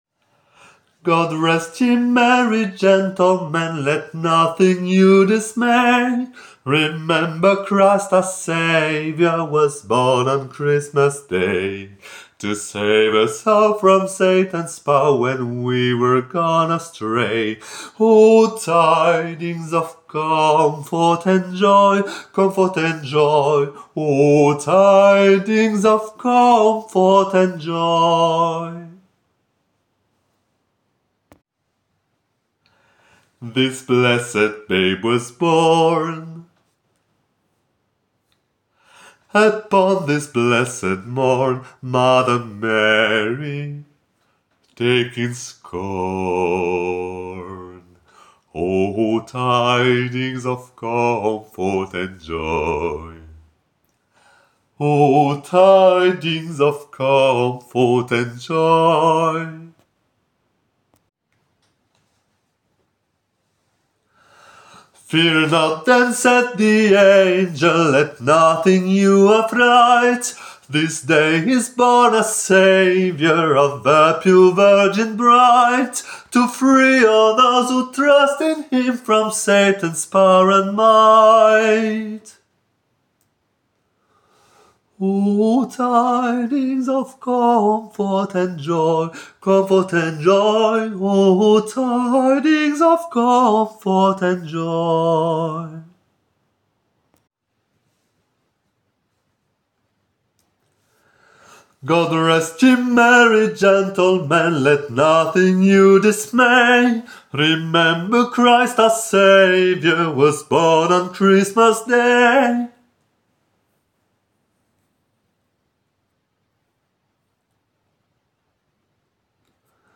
basse
GOD-REST-YE-MERRY-GENTLEMEN-basse.aac